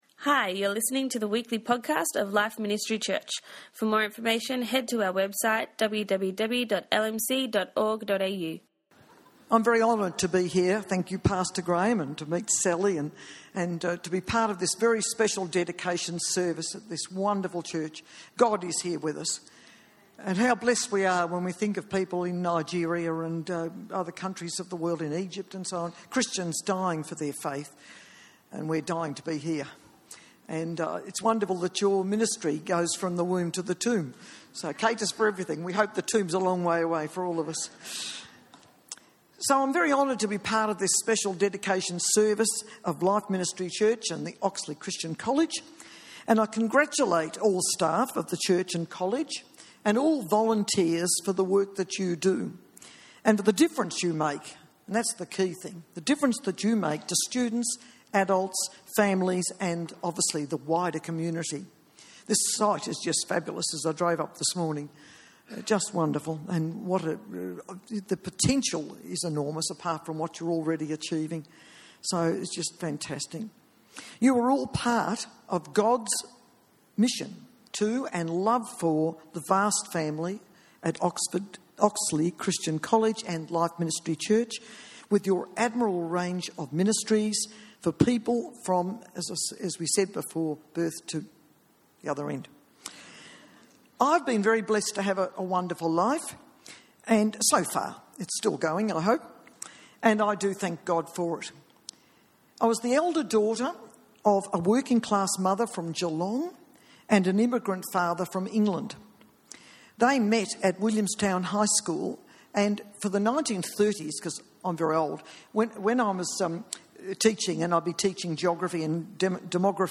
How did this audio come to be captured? Staff Dedication Service 2015